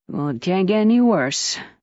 Add Juni Mission Voice Files